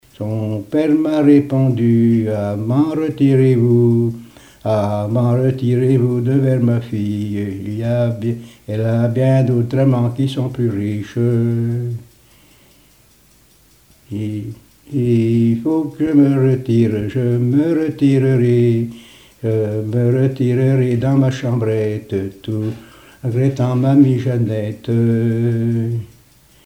chansons et témoignages parlés
Pièce musicale inédite